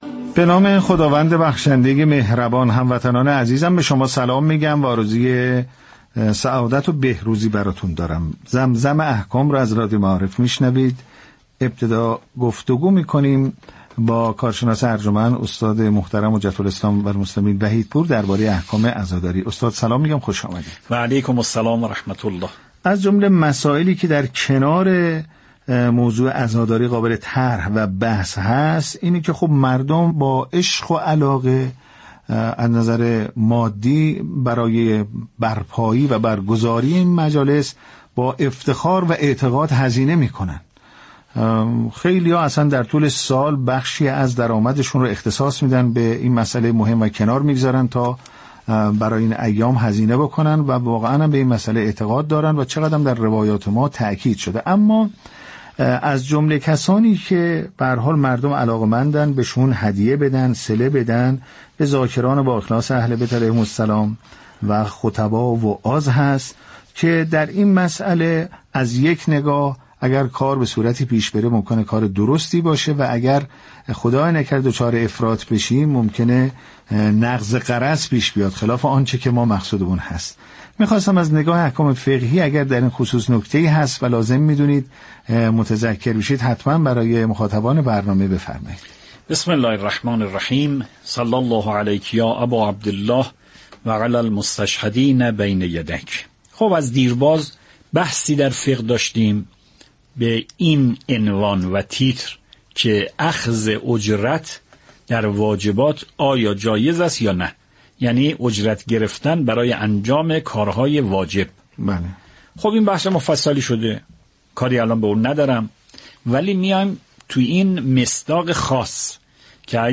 برنامه رادیویی «زمزم احكام» تلاش دارد تا با زبانی روان و كارشناسی دقیق، ضمن تبیین صحیح احكام الهی، راهكارهای عملی در زندگی روزمره را به شنوندگان ارائه دهد و آنان را در پایبندی بیشتر به شریعت یاری رساند.